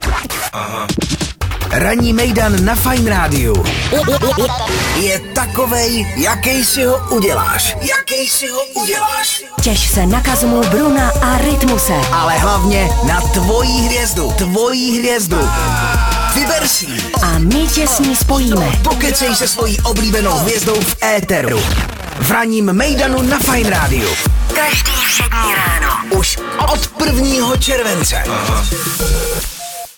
TEASER RANNÍ MEJDAN FAJNRADIA